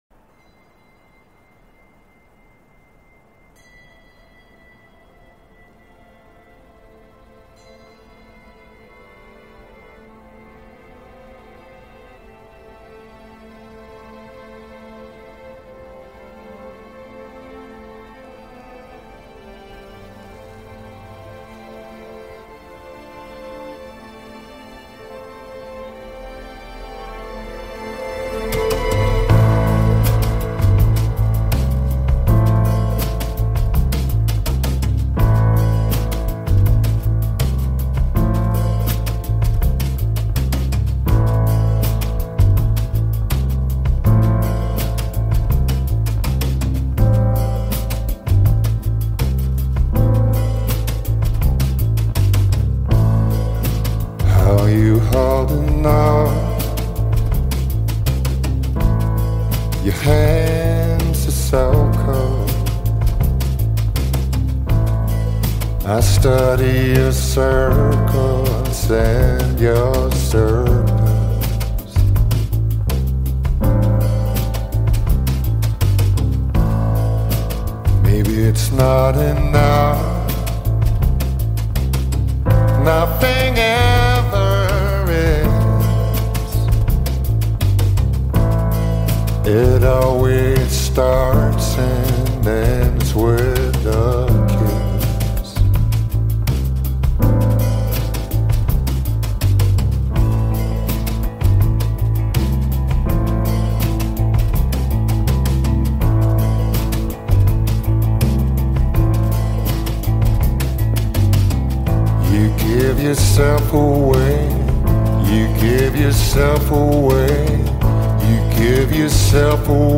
Die Musik kommt dieses Mal aus Norwegen - von einer meiner Lieblingsbands.